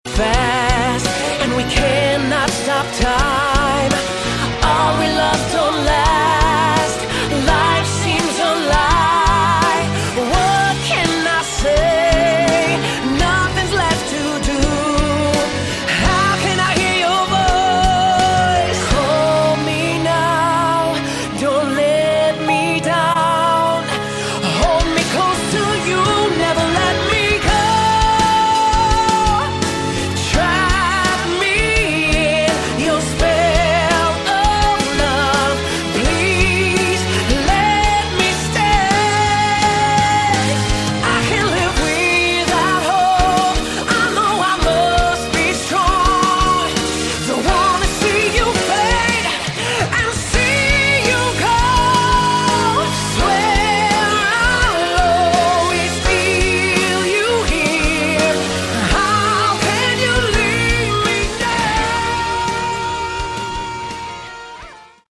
Category: Melodic Metal
bass, keyboards
guitar
drums
backing vocals